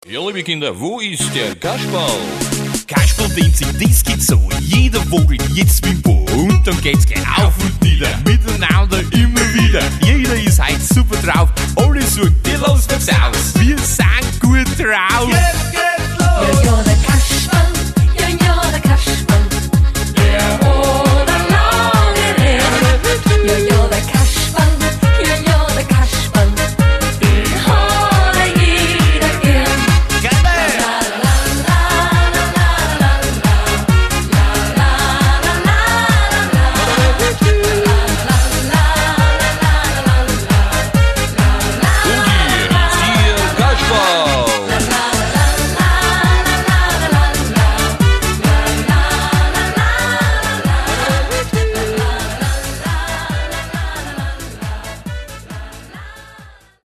AUS DEM APRES-SKI BEREICH . . .